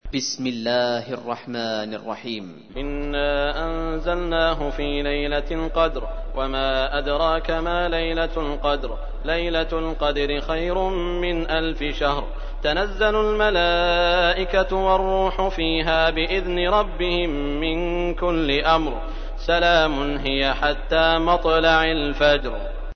تحميل : 97. سورة القدر / القارئ سعود الشريم / القرآن الكريم / موقع يا حسين